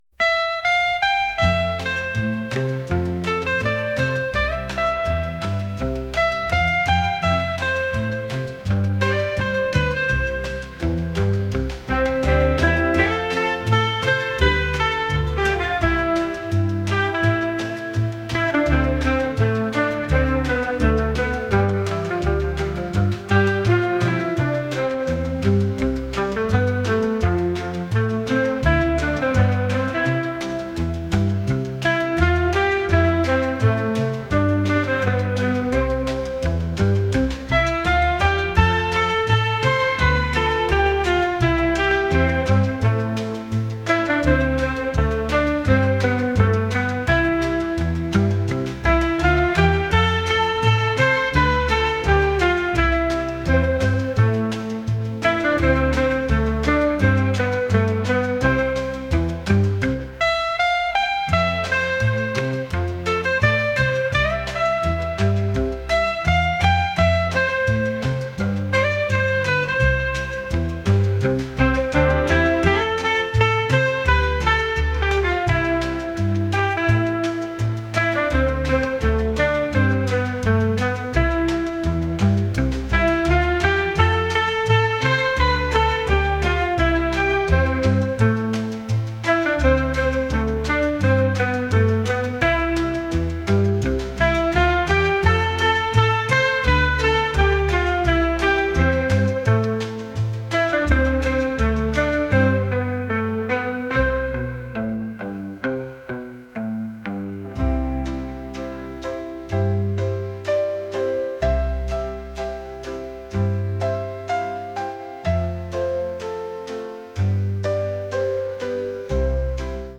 pop | reggae